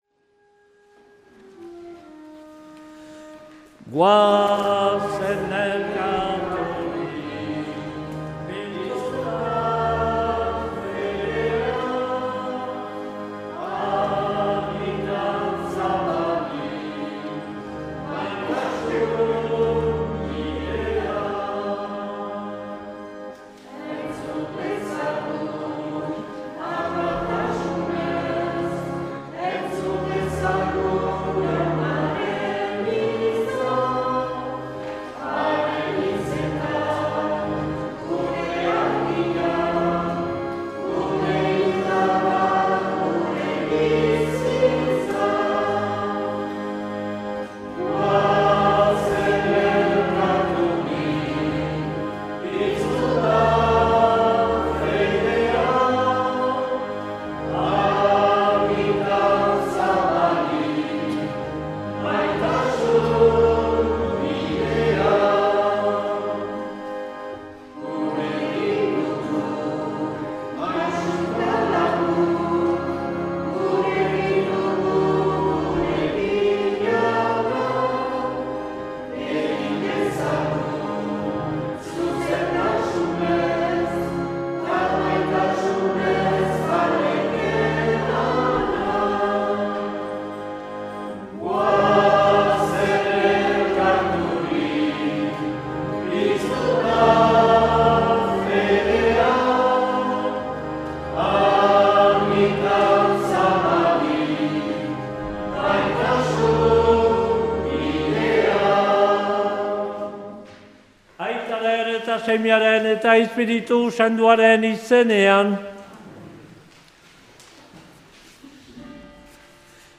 2023-01-29 Urteko 4. Igandea A - Aldude